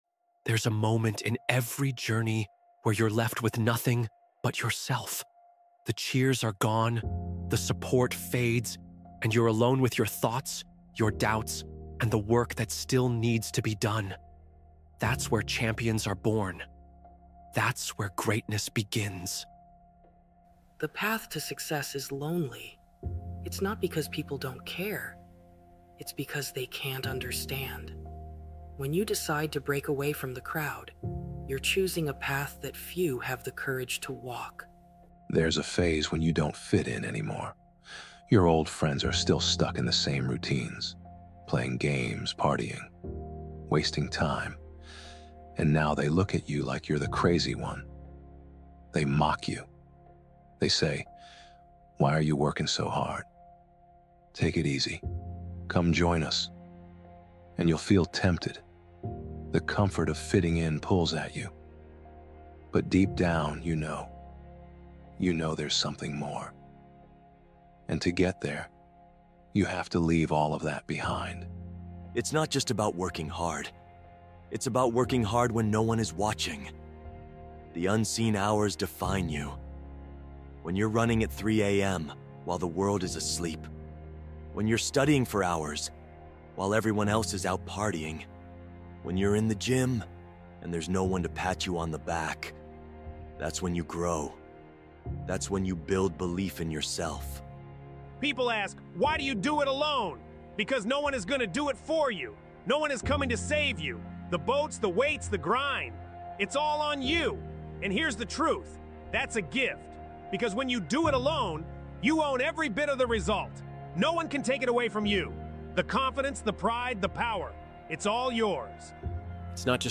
Do It Alone | Anime Motivational Speech